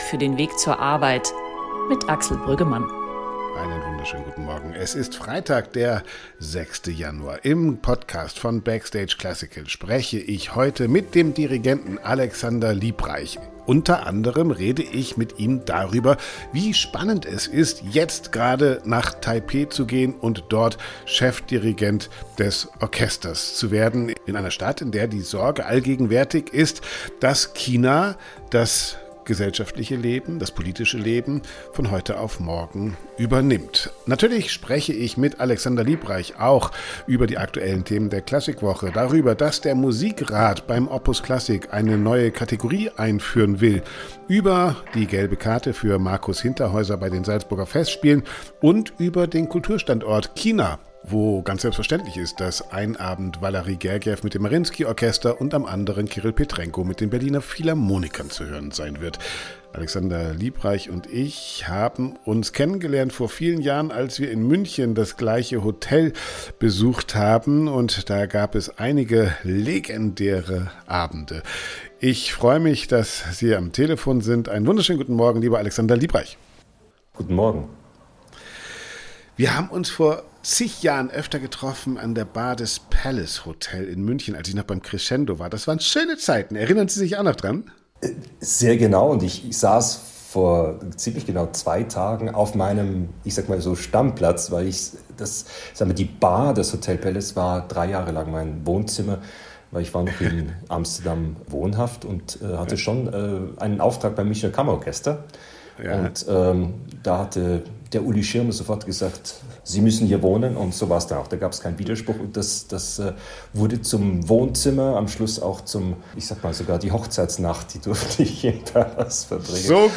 Im Podcast-Gespräch mit BackstageClassical äußert sich Liebreich kritisch zur deutschen Kultur- und Bildungspolitik sowie zur internationalen Lage im Musikbetrieb. Liebreich bemängelt den geringen Stellenwert der Musikvermittlung in Deutschland.